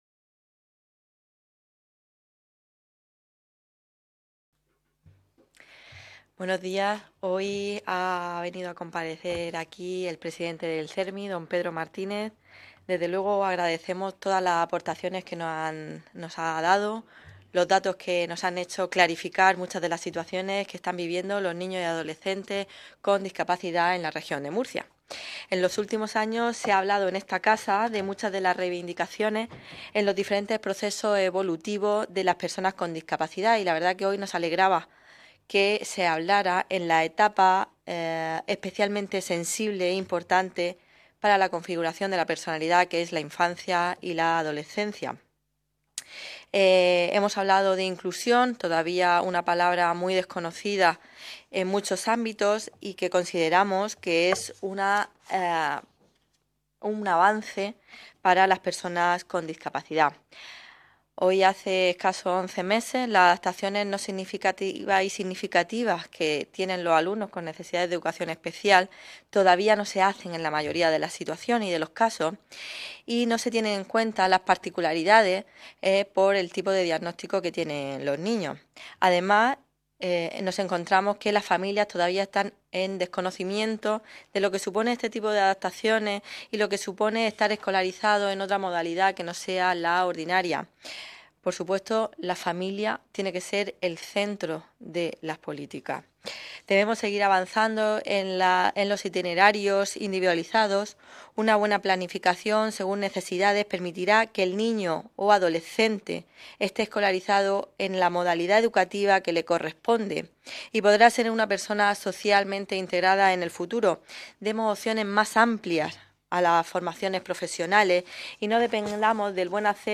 Ruedas de prensa tras la Comisión Especial de Estudio sobre Infancia y Adolescencia